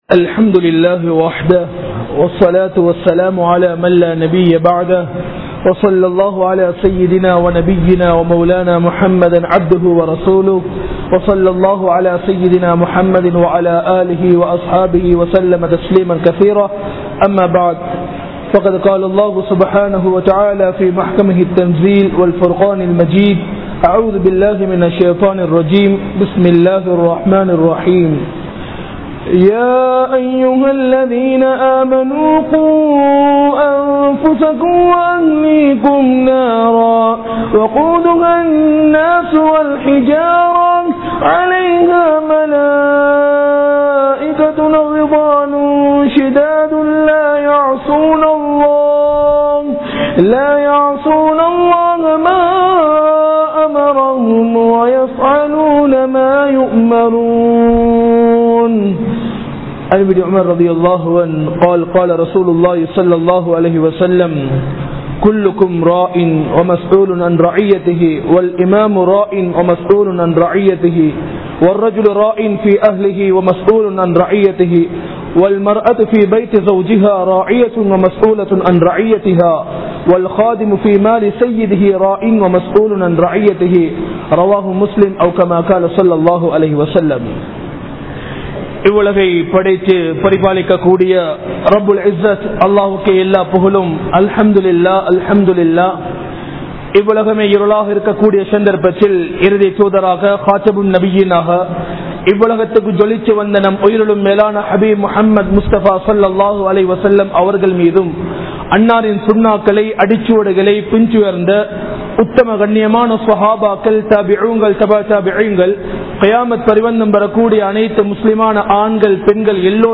Pillaihalin Kadamaihalai Marantha Pettroarhal (பிள்ளைகளின் கடமைகளை மறந்த பெற்றோர்கள்) | Audio Bayans | All Ceylon Muslim Youth Community | Addalaichenai
Panadura, Masjithul Ummi Jumua Masjith-Adam Forest Place